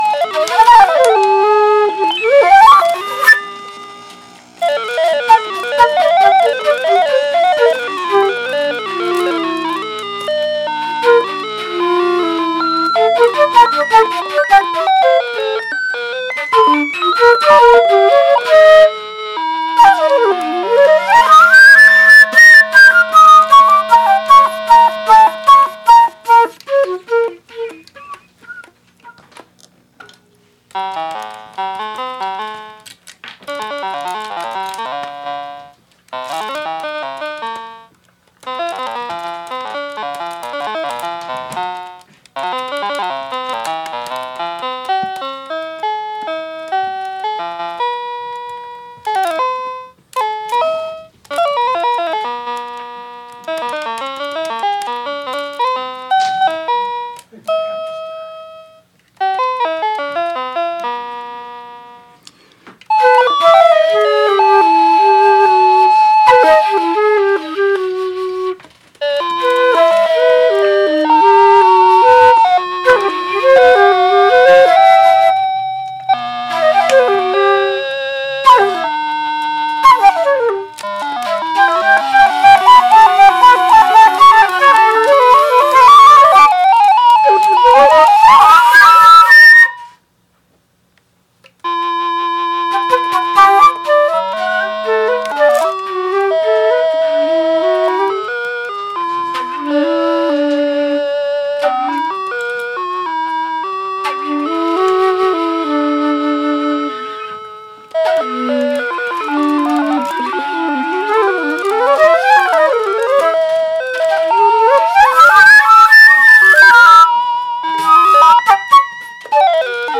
special live improvised performance!
Avant-Garde Jazz Live Performance